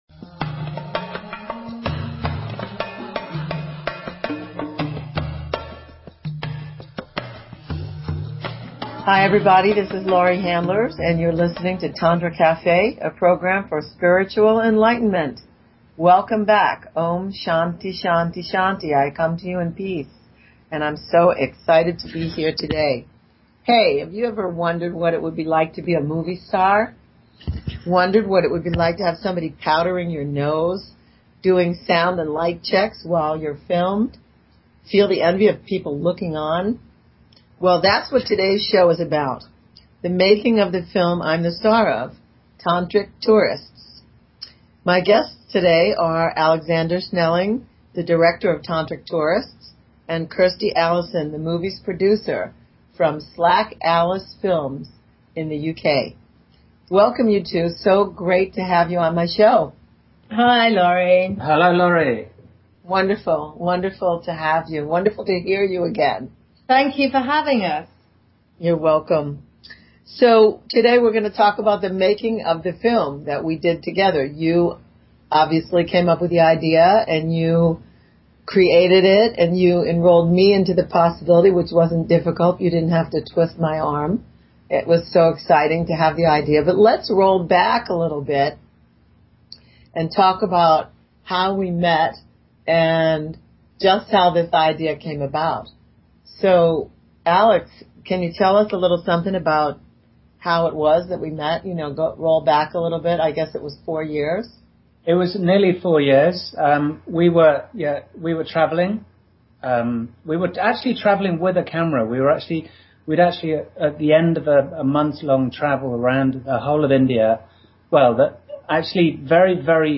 Talk Show Episode, Audio Podcast, Tantra_Cafe and Courtesy of BBS Radio on , show guests , about , categorized as